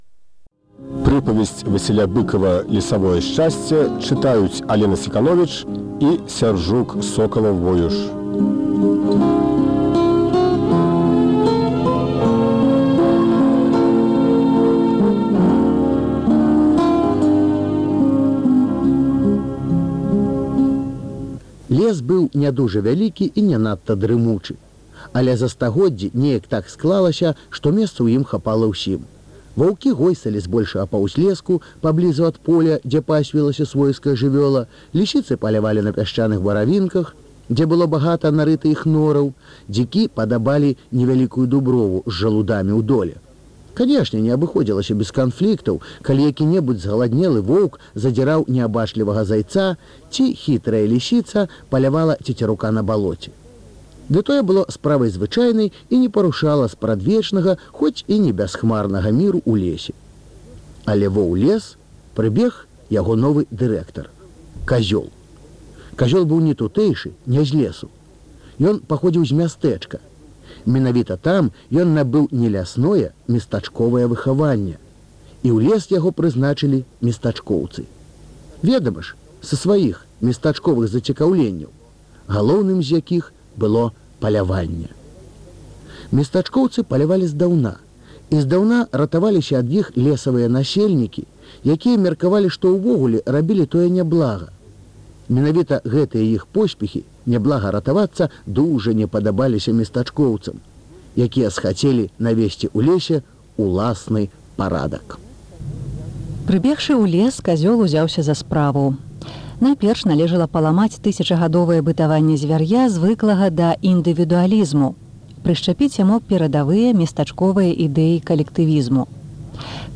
Архіўны запіс.